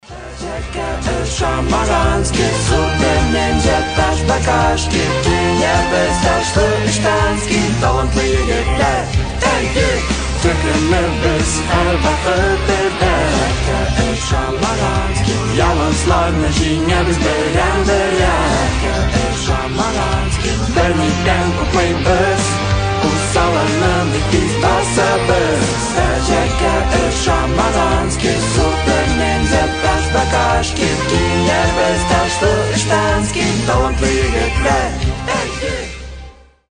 Заставка мультсериала